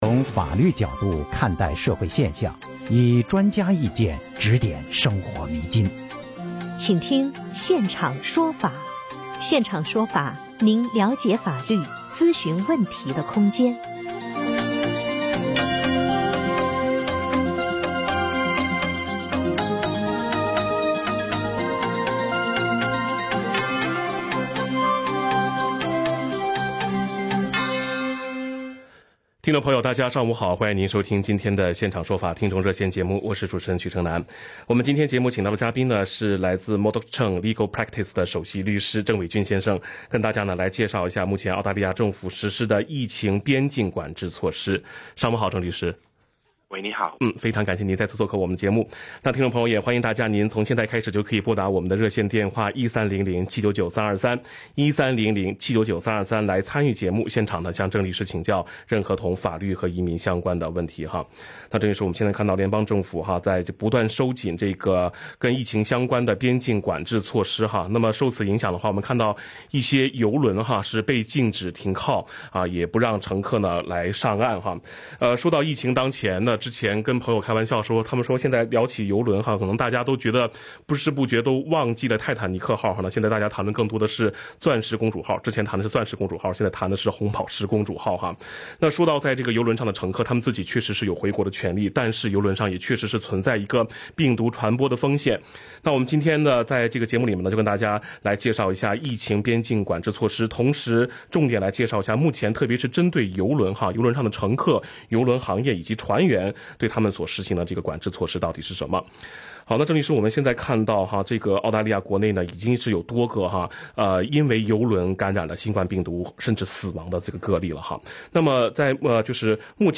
legal_talkback_march_31_01.mp3